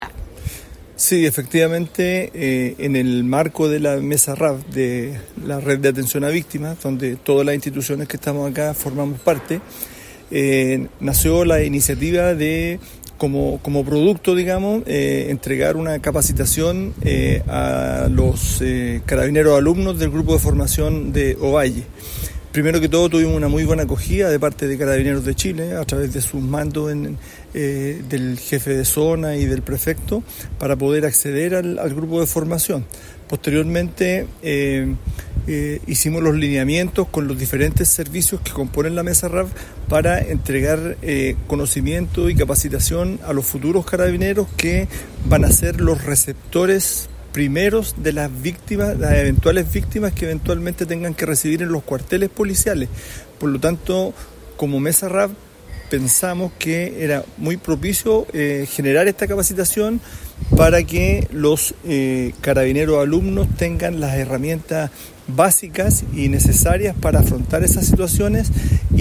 Abel Lizama, Coordinador Regional de Seguridad Pública, destacó
Abel-Lizama-Coordinador-Regional-de-Seguridad-Publica.mp3